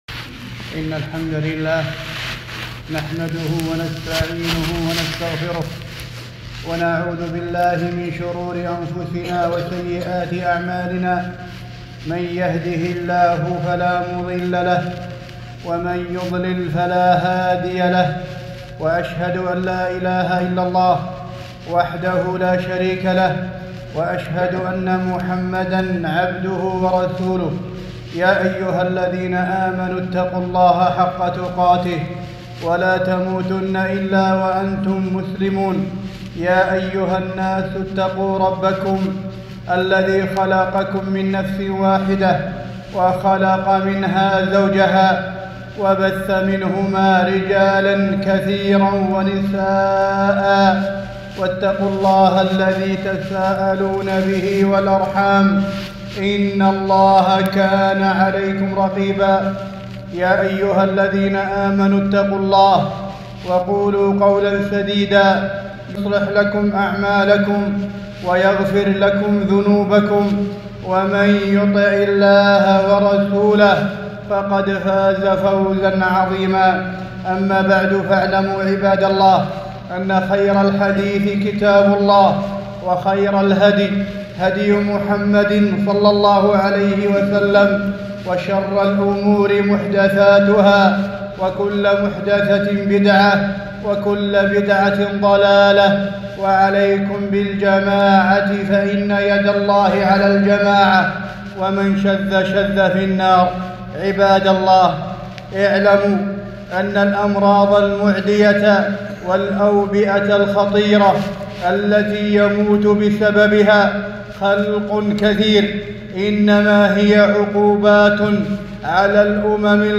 خطبة - فيروس كورونا